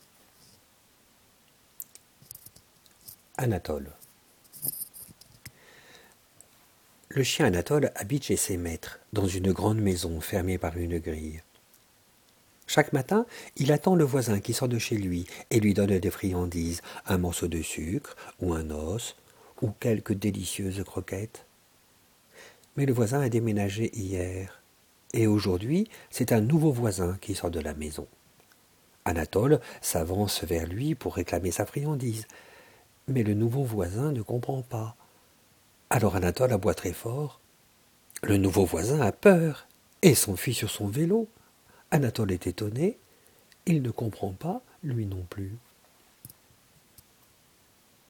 Elle permettra aux enseignants, orthophonistes, rééducateurs, psychologues ou chercheurs d’évaluer le niveau de compréhension d’enfants de 5 à 8 ans qui écoutent la lecture à haute voix de trois récits non illustrés (cf. les parties I et II du document de présentation de l'épreuve et les enregistrements sonores).